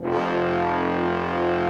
BRASS 3 F2.wav